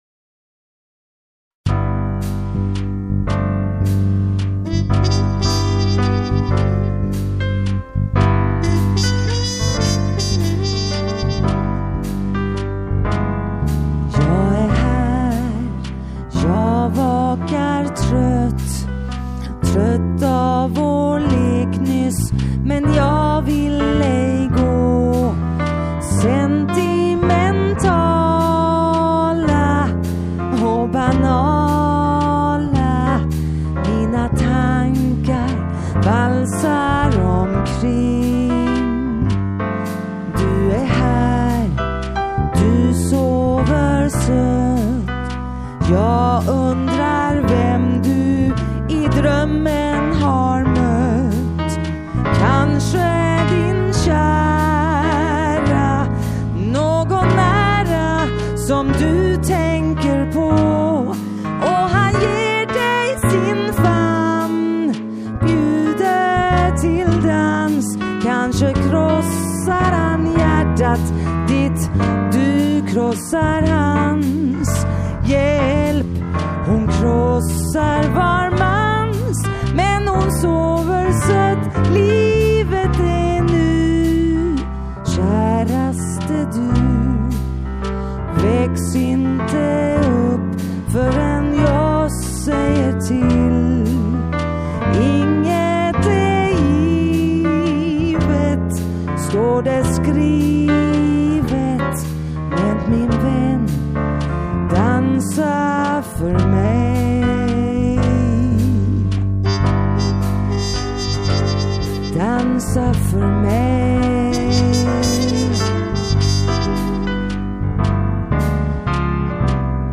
Sång
Trumpet